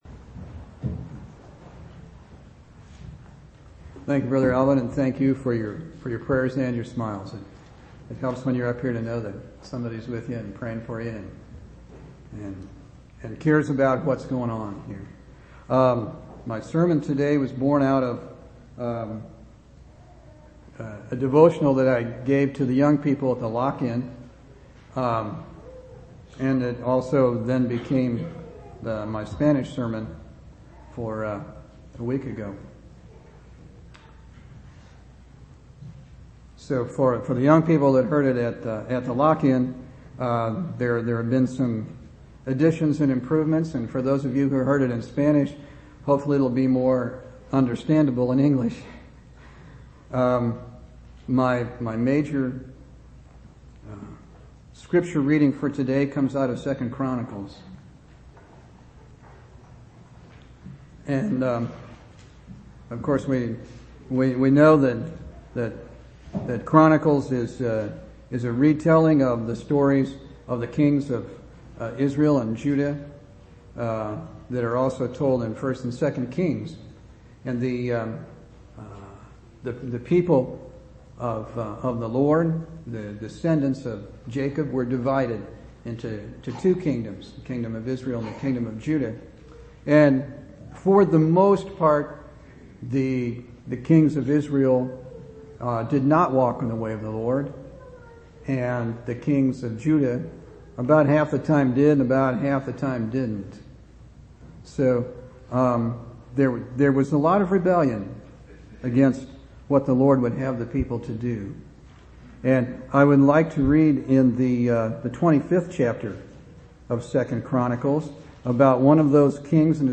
1/23/2005 Location: Temple Lot Local Event